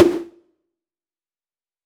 REDD PERC (20).wav